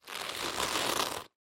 На этой странице собраны разнообразные звуки поворота головы – от легкого шелеста до выраженного хруста.
Звук поворота головы